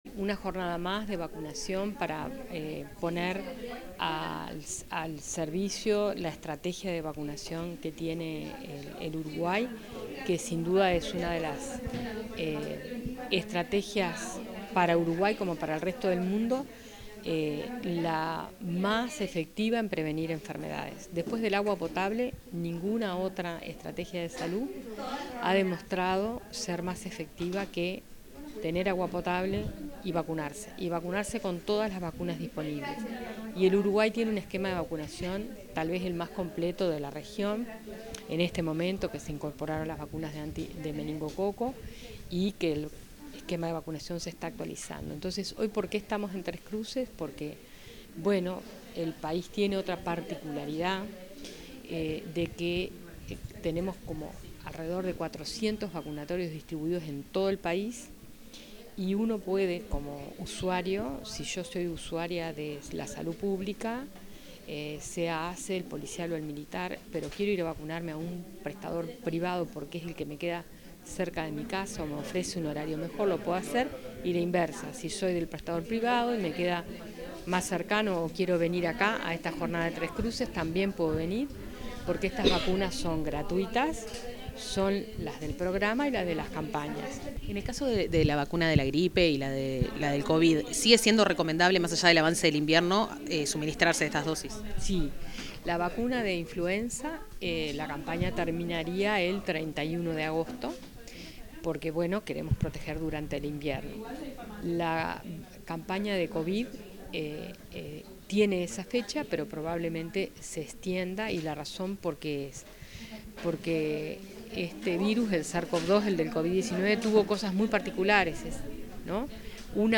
Declaraciones de la directora de Inmunizaciones, Catalina Pírez